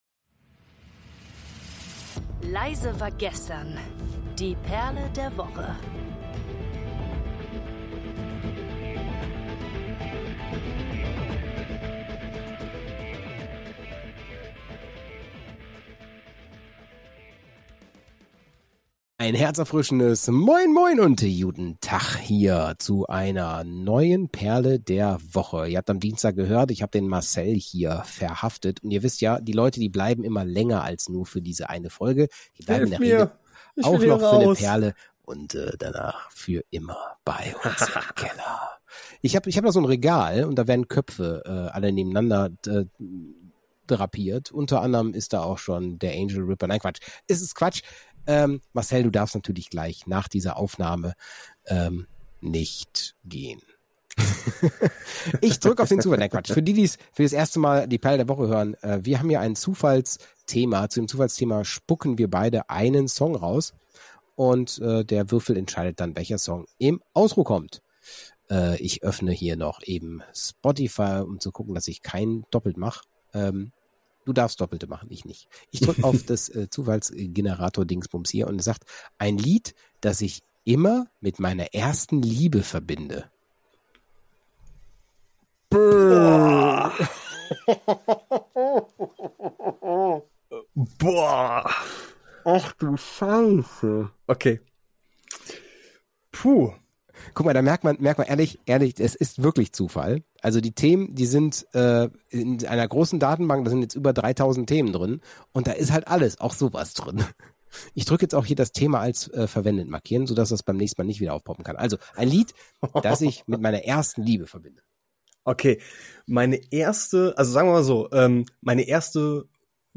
Zwischen Lachen und ernst gemeinter Reflektion entsteht ein Gespräch über Musik als emotionalen Speicher – und über all die kleinen Geschichten, die mit Songs verbunden sind.